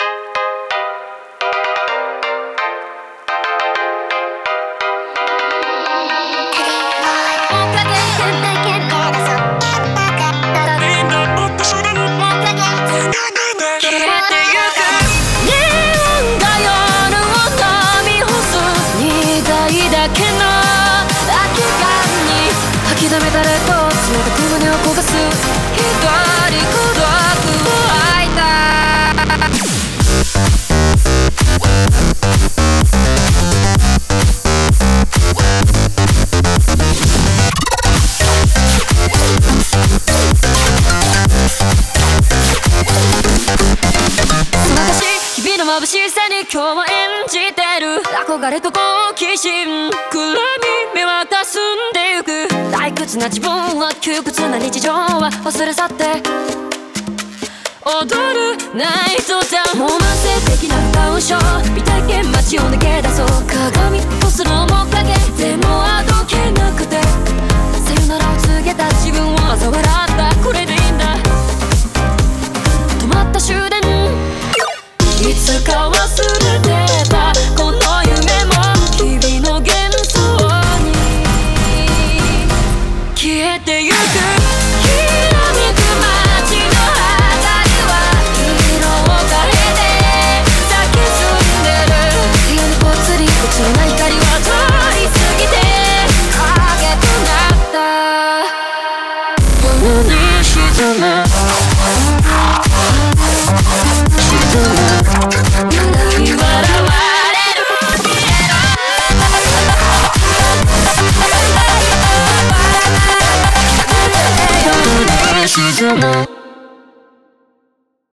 BPM128
Audio QualityPerfect (High Quality)
- Audio volume slightly lowered.